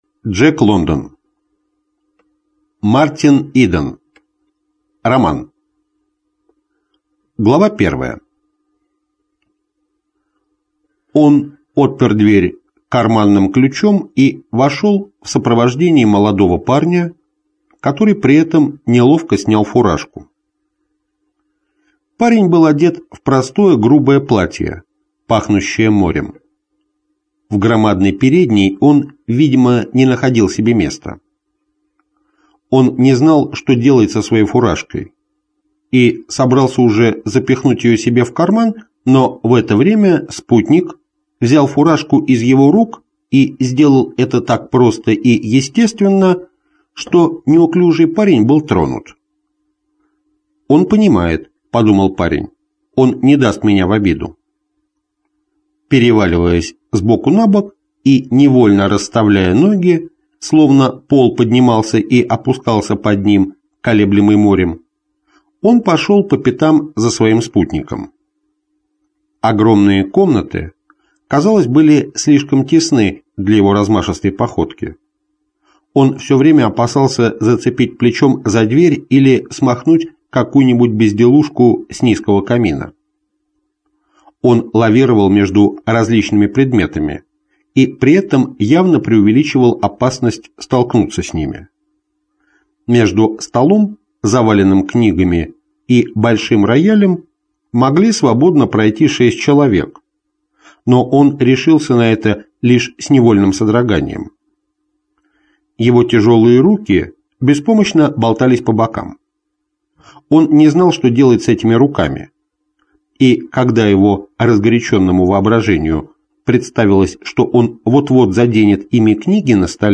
Аудиокнига Мартин Иден - купить, скачать и слушать онлайн | КнигоПоиск
Аудиокнига «Мартин Иден» в интернет-магазине КнигоПоиск ✅ Зарубежная литература в аудиоформате ✅ Скачать Мартин Иден в mp3 или слушать онлайн